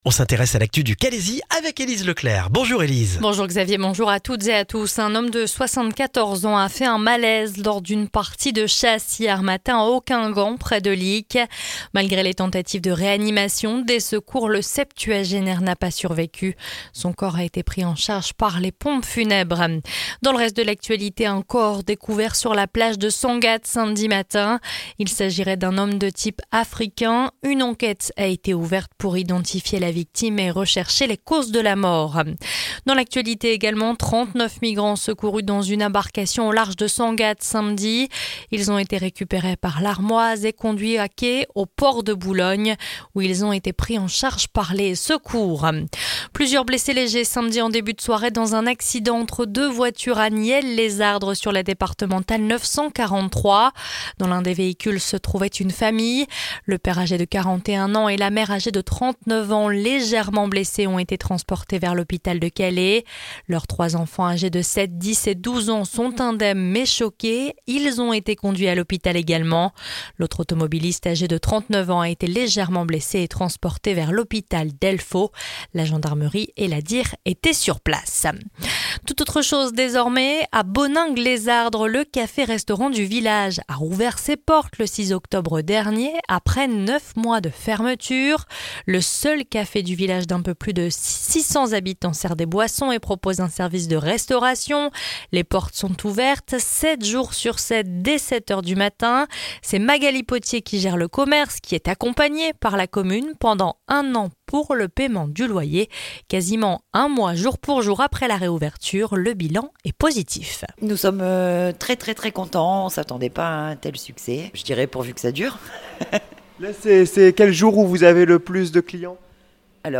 Le journal du lundi 4 novembre dans le Calaisis